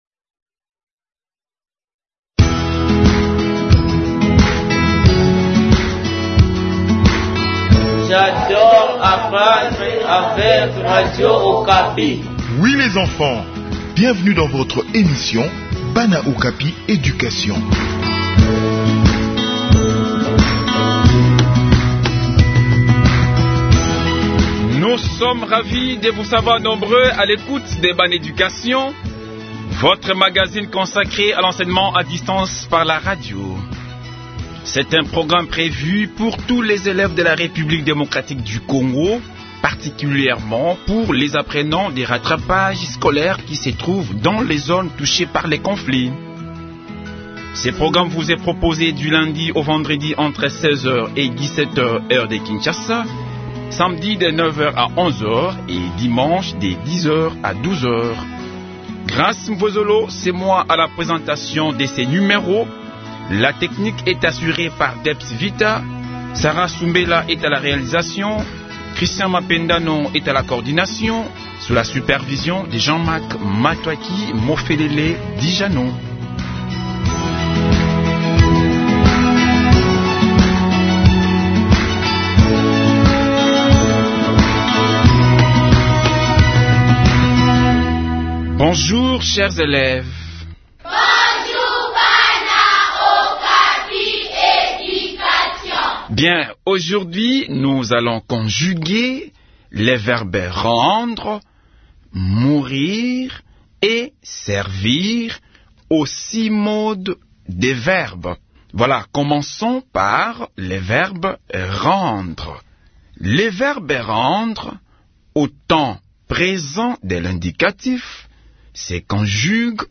Enseignement à distance : leçon de conjugaison du verbe Rendre, Mourir et Servir